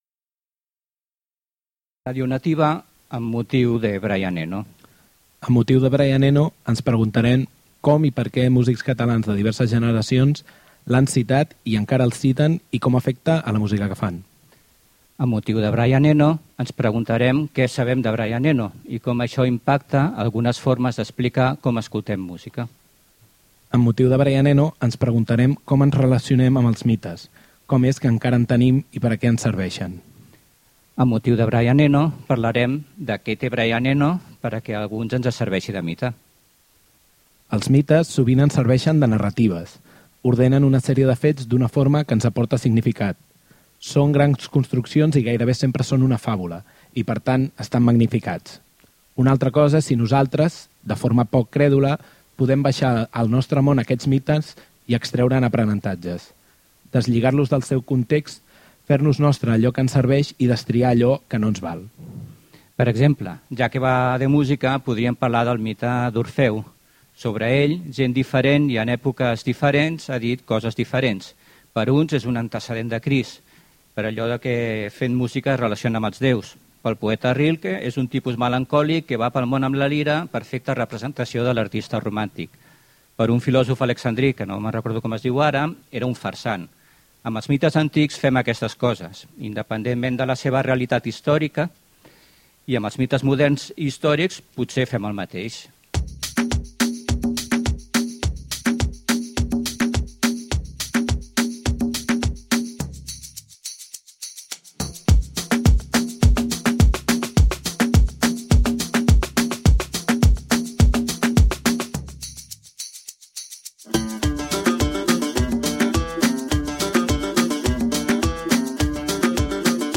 Programa especial de Ràdio Nativa, realitzat i emès el 26 de Setembre de 2017.
Conversa